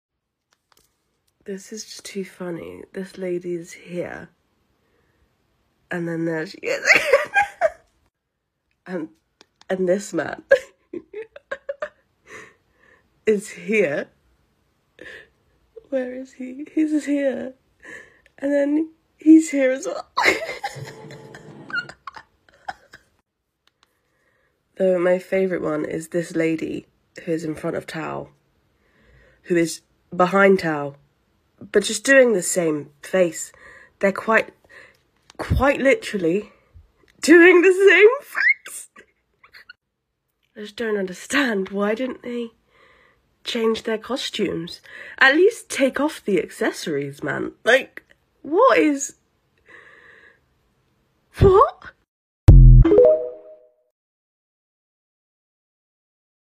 Heartstopper simulated audience